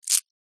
Звуки фисташек
Здесь собраны уникальные записи: от мягкого шелеста скорлупы до насыщенного хруста при разламывании.